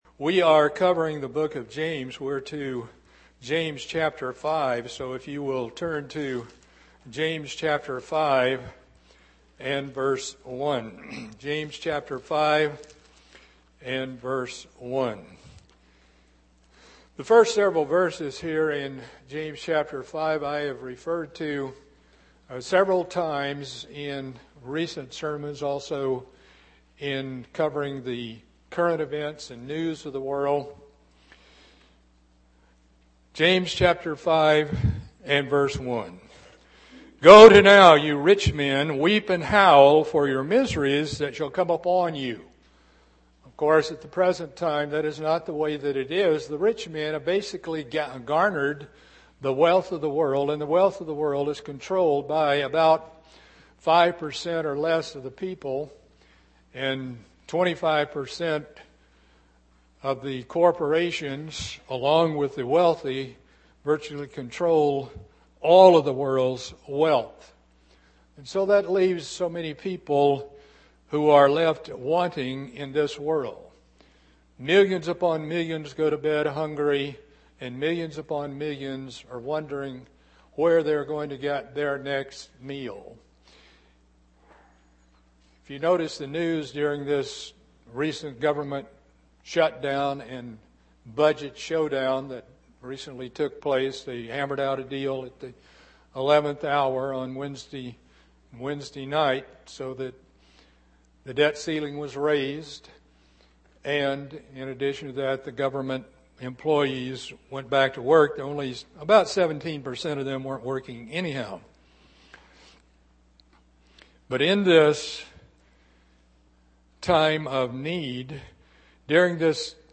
Part 4 of a Bible study on the book of James. Covering chapter 5.